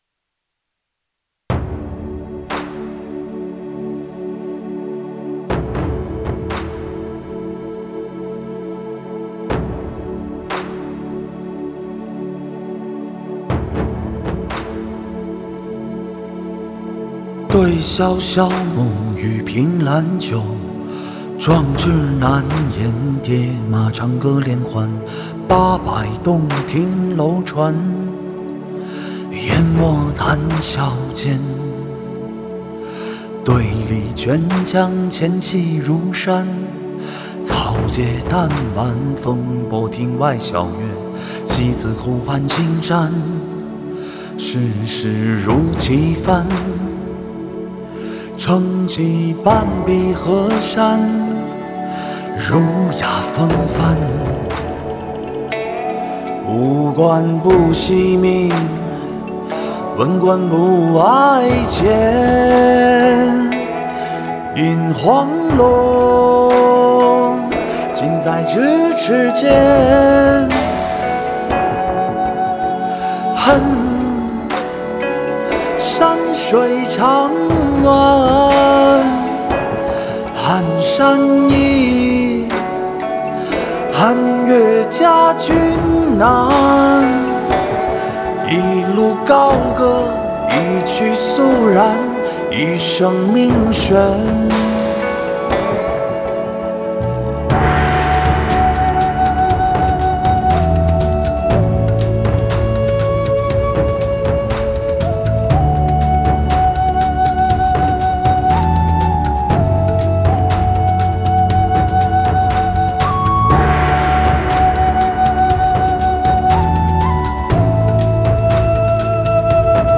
Ps：在线试听为压缩音质节选
主唱/吉他
贝斯
键盘